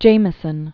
(jāmĭ-sən), Judith Born 1943.